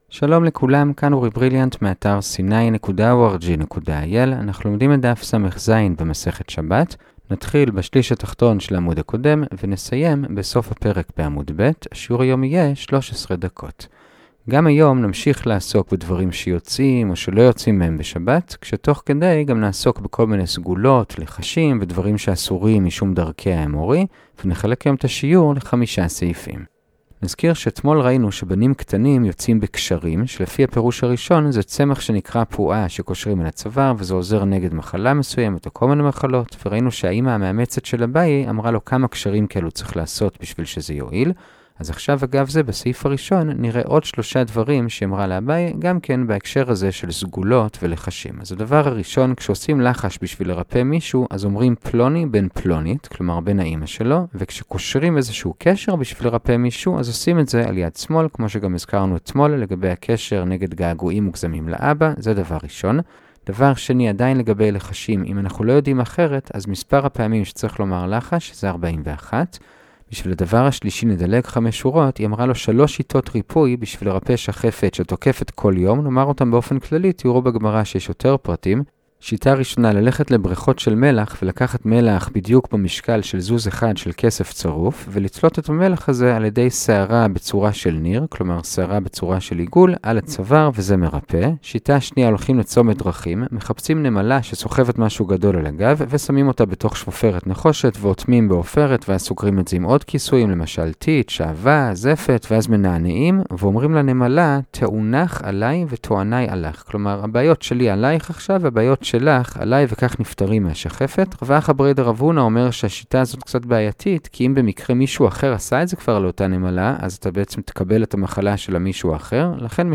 הדף היומי - שבת דף סז - הדף היומי ב15 דקות - שיעורי דף יומי קצרים בגמרא